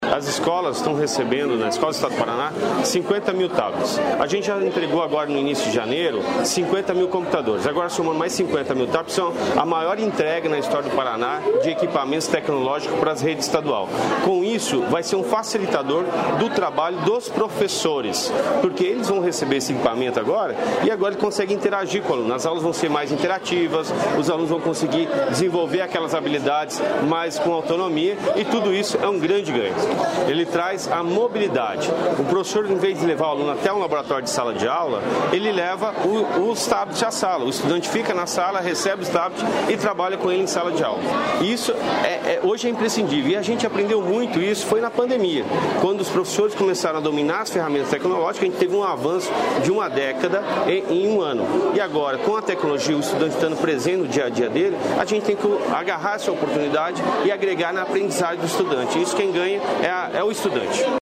Sonora do secretário da Educação, Roni Miranda, sobre entrega de tablets para alunos da rede estadual